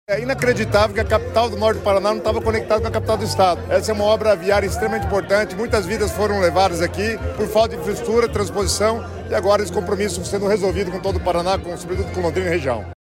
Sonora do secretário das Cidades, Guto Silva, sobre a duplicação da PR-445 de Mauá da Serra a Lerroville